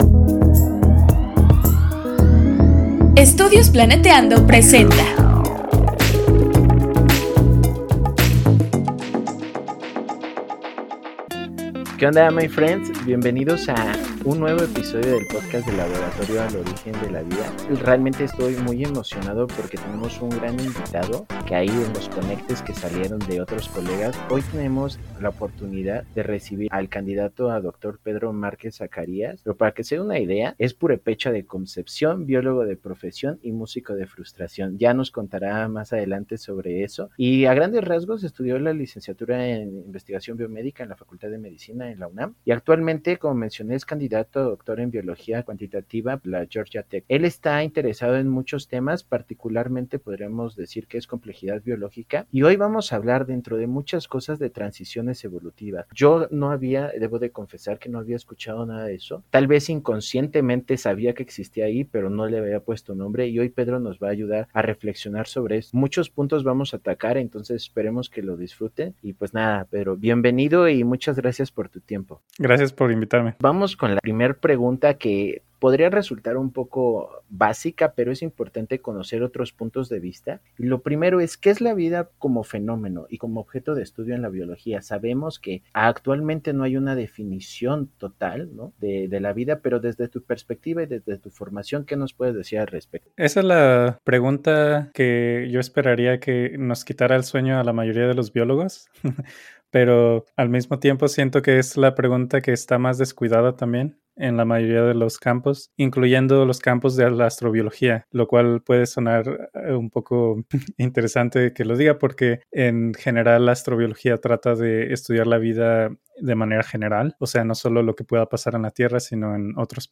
La biología teórica es una gran herramienta que permite describir nuevos niveles de organización así como desarrollar nuevas estrategias para entender los sistemas complejos Entrevista